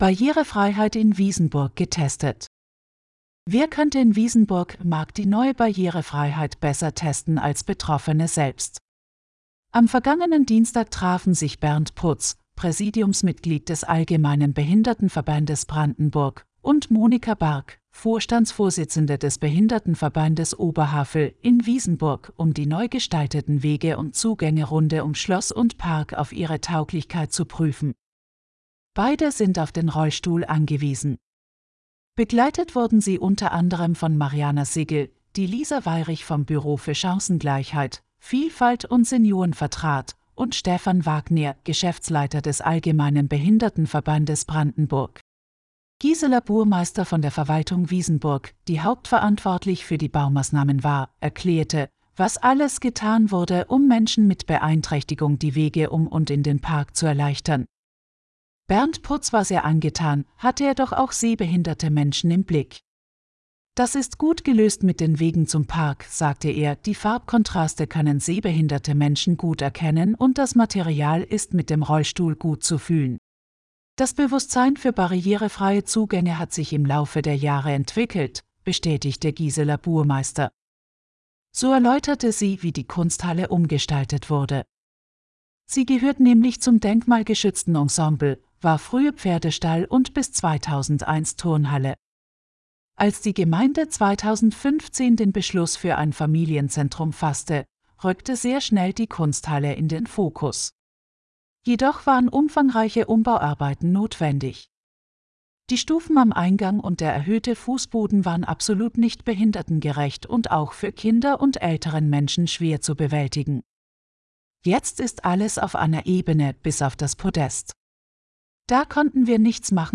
Ab sofort können Sie einige unserer Artikel auch hören. Eine KI macht es möglich.